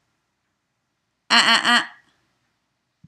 So, how do I ensure that ‘no’ (or my more preferred “no-no noise” found below) is actually heeded?
eh-eh-eh.m4a